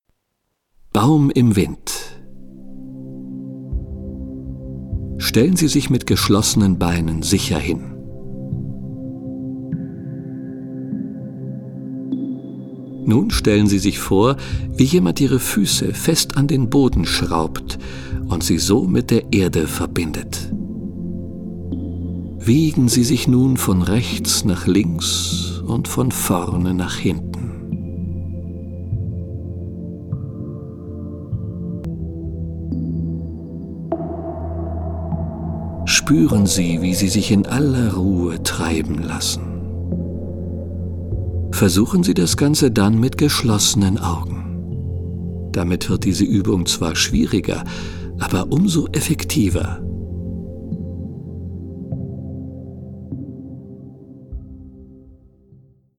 Die ruhige und einfühlsame Stimme des Sprechers
führt sie durch die einzelnen Übungen, die mit spezieller Entspannungsmusik unterlegt sind.